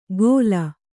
♪ gōla